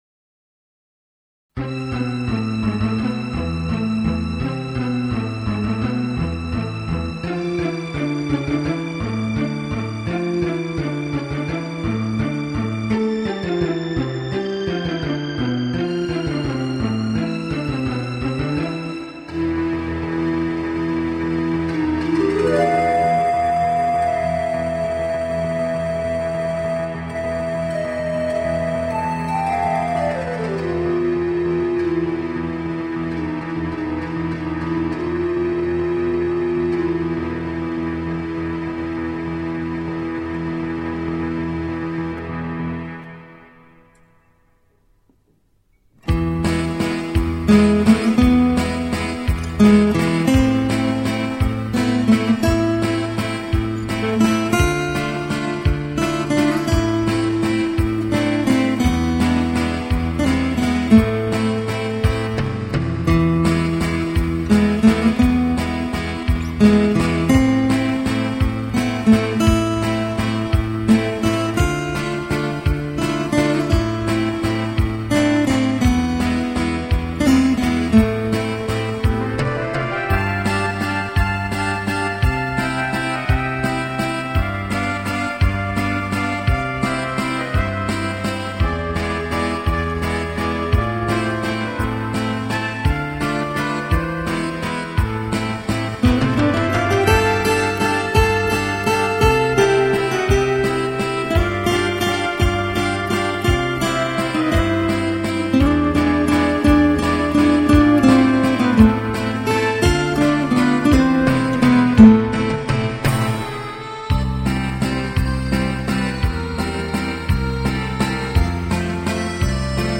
0148-吉他名曲多瑙河之波.mp3